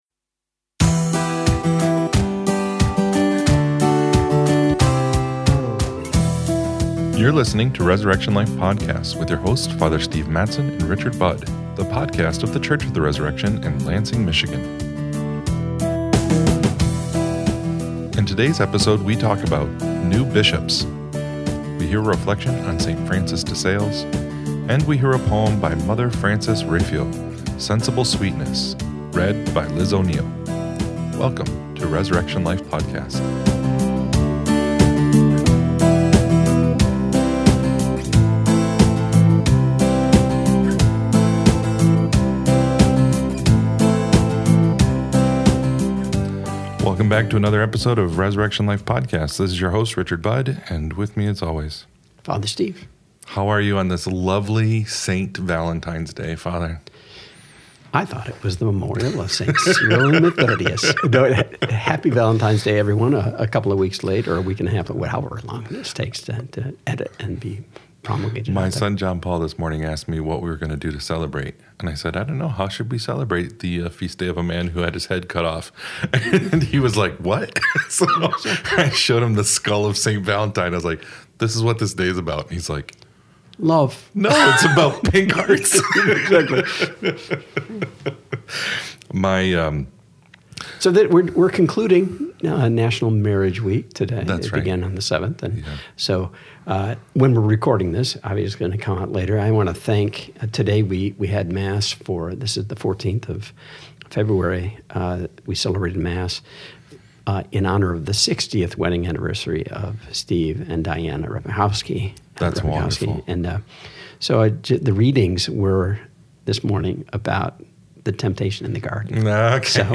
In today’s episode, we talk about new bishops. We hear a reflection on Saint Francis de Sales.